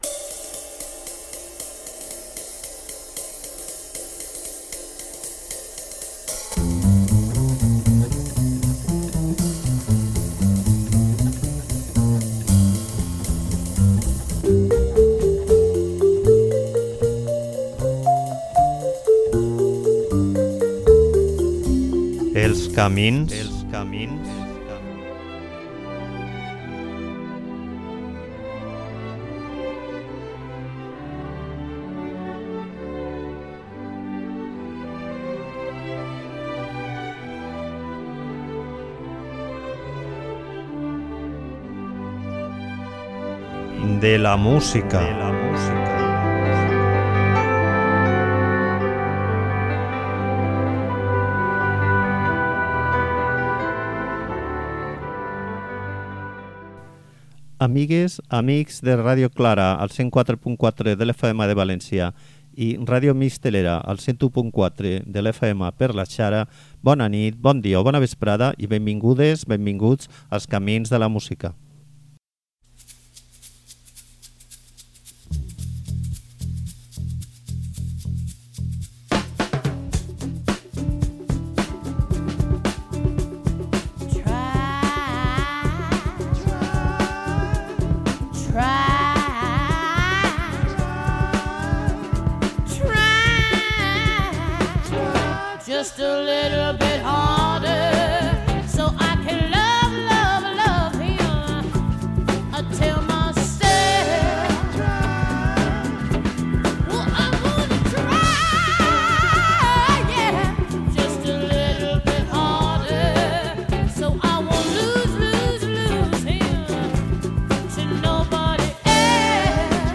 Gaudim de les gravacions què ens va deixar i deixem-nos dur de la seua explosiva vitalitat en interpretar eixa mescla de blues i rock que la va caracteritzar.